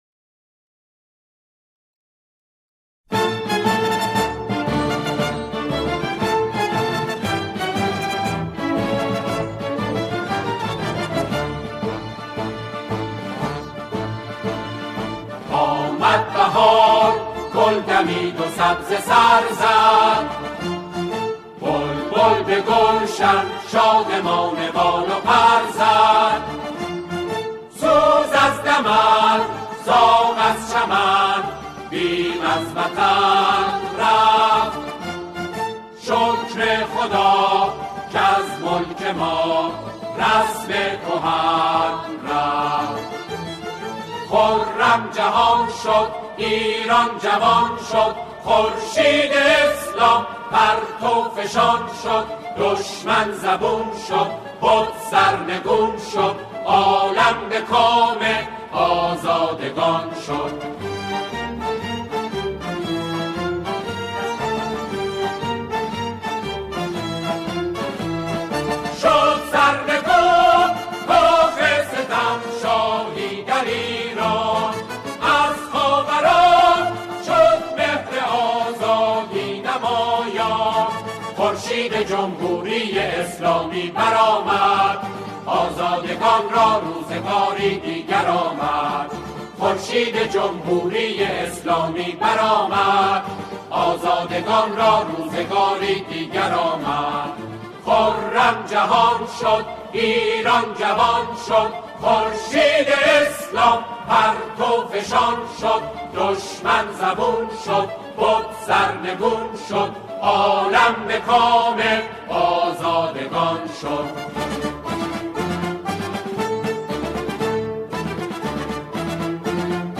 سرودهای انقلابی